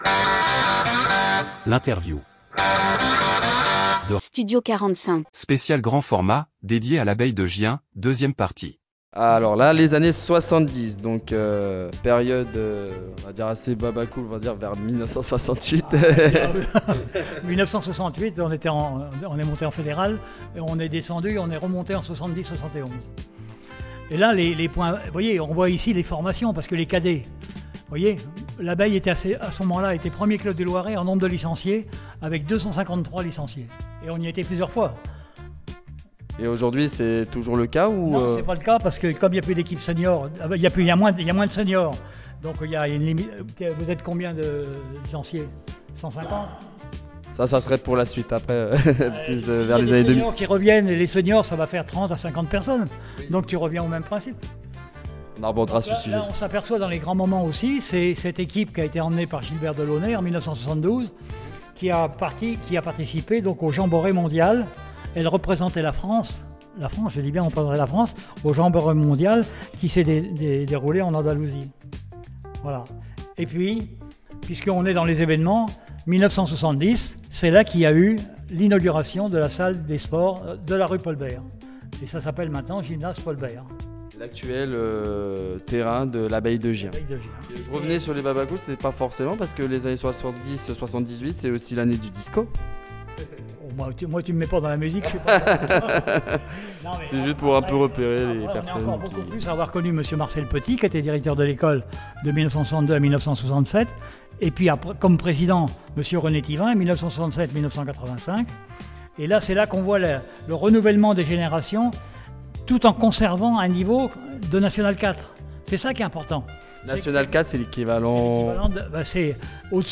Interview Studio 45 - Les 100 ans de l’Abeille de Gien – Partie 2